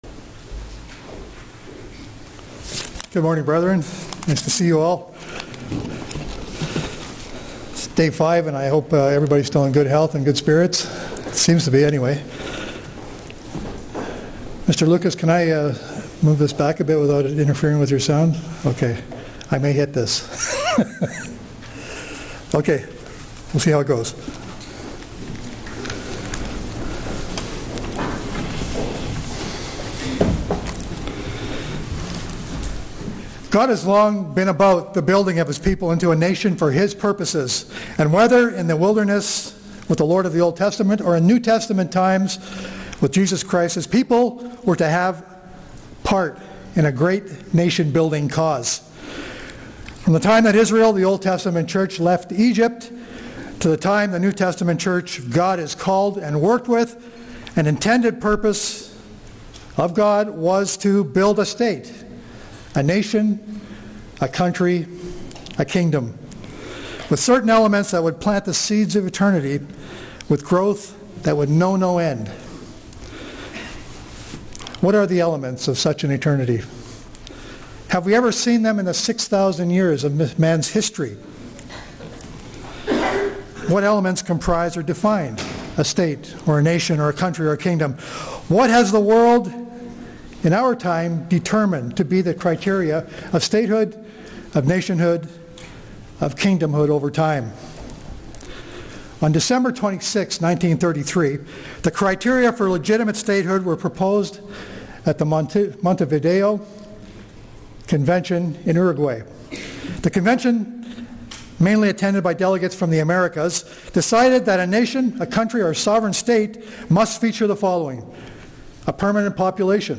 Feast of Tabernacles Sermon kingdom of god Studying the bible?